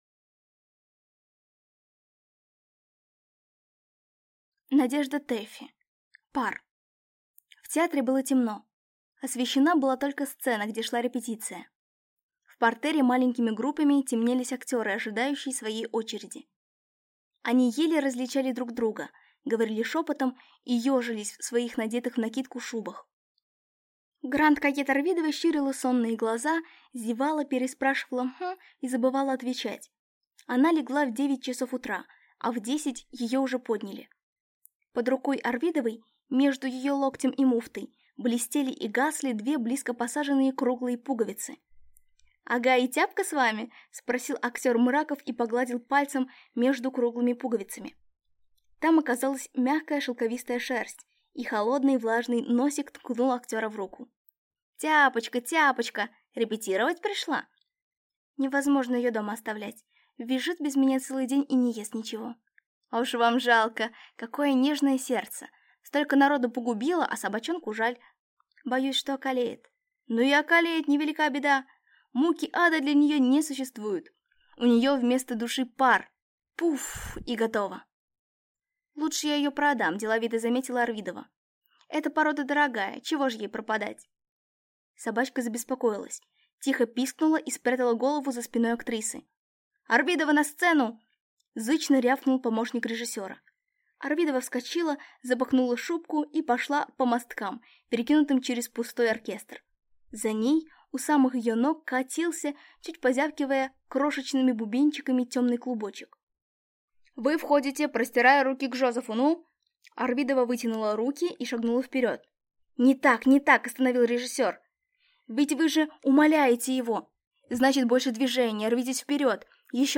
Аудиокнига Пар | Библиотека аудиокниг